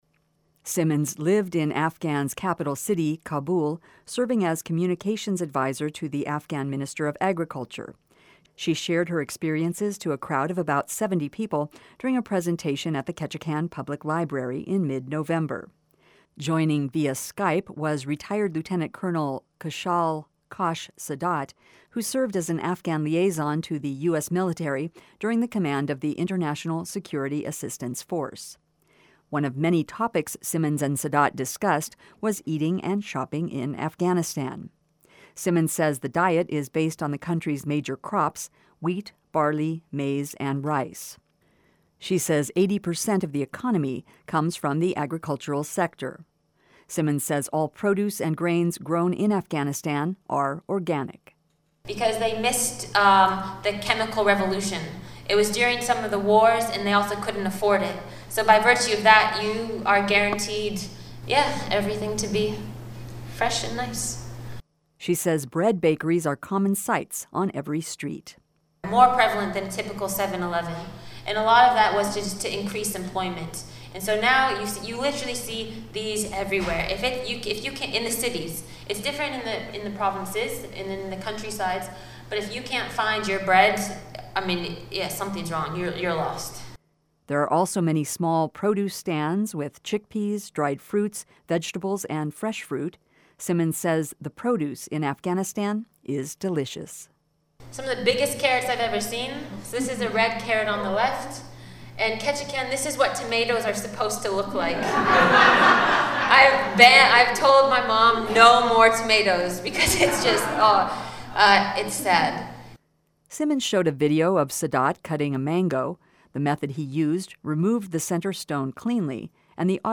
She shared her experiences to a crowd of about 70 people during a presentation at the Ketchikan Public Library in mid-November.